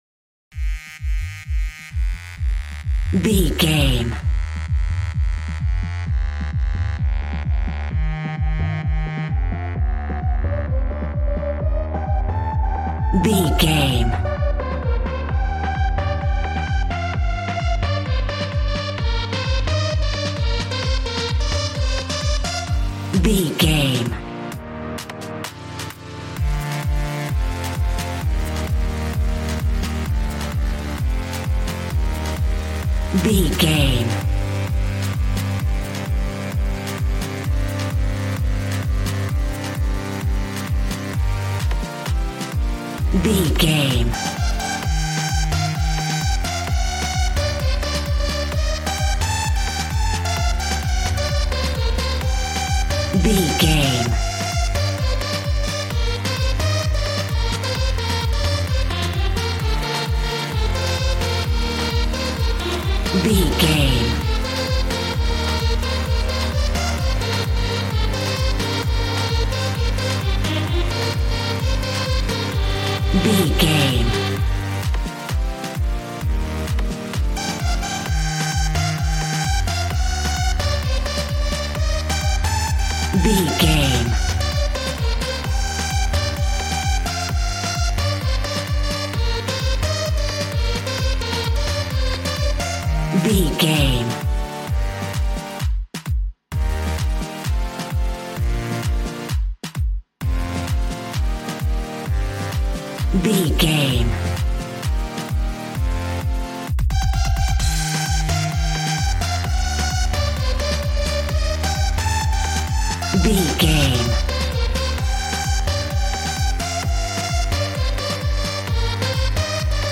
Aeolian/Minor
Fast
uplifting
lively
groovy
synthesiser
drums